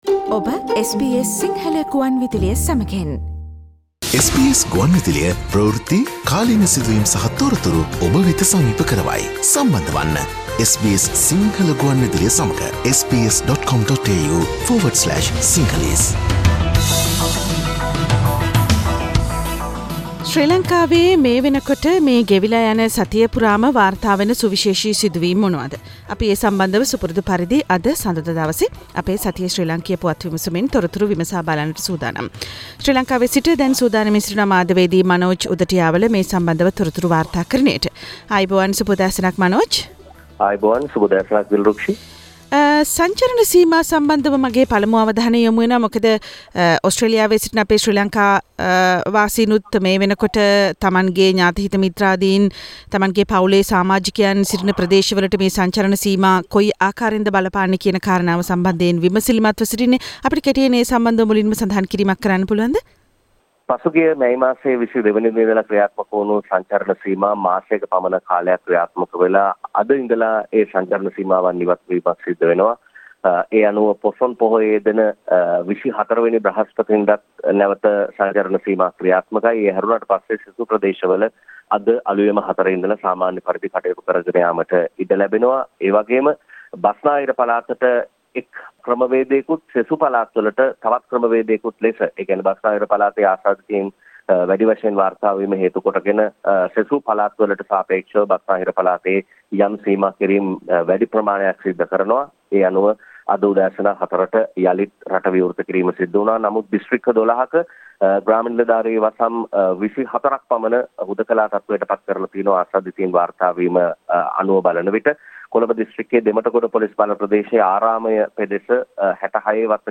Allegations that government's small parties are being undermined: Weekly news highlights from Sri Lanka wrap